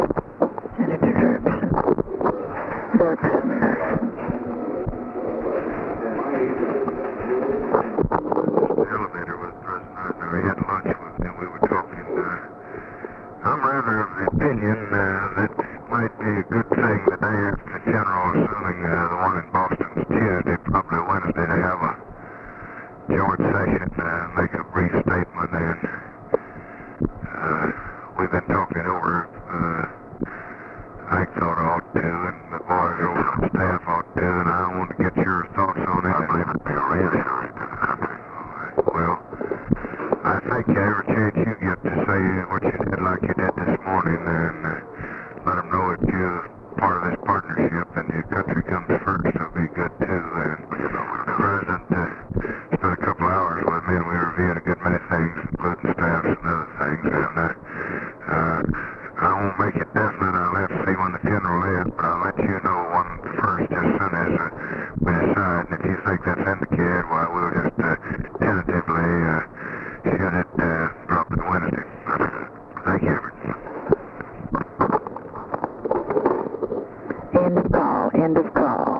Conversation with EVERETT DIRKSEN, November 23, 1963
Secret White House Tapes